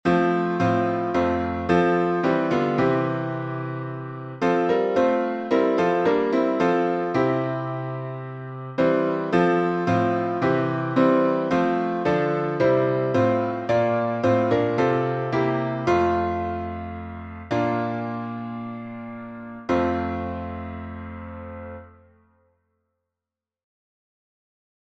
Key signature: F major (1 flat) Time signature: 4/4 Meter: 6.6.8.6.(S.M.)